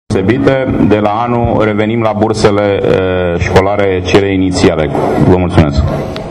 Prezent la deschiderea anului de invatamant la Scoala numarul 96, de pe strada Rezonantei, edilul Sectorului 4, Cristian Popescu-Piedone, a afirmat ca, incepand cu exercitiul bugetar urmator, bursele școlare vor reveni la cuantumul normal.